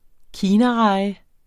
Udtale [ ˈkiːna- ]